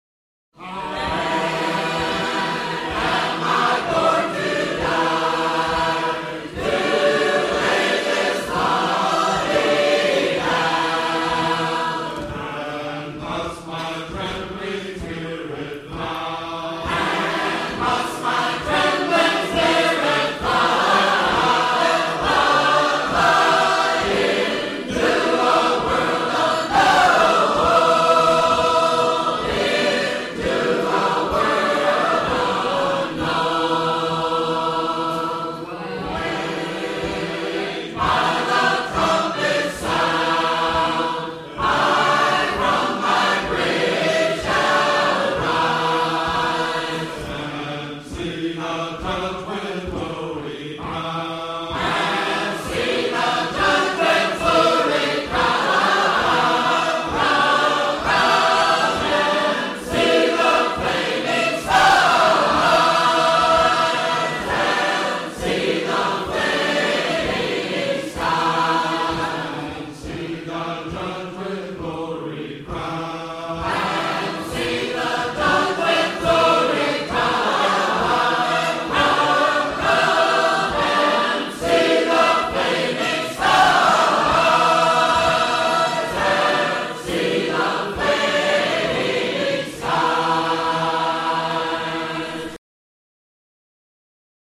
Rejoice with singers from the 1998 Missouri singing convention in a lovely country church near Marthasville, Missouri.
When they arrive, they bring the power of their voices as well as the joy of their fellowship.